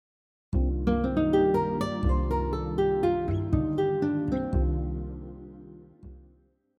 Example 3 incorporates the minor 7 arpeggio with other scale and chromatic notes.
minor 7 arpeggio example 3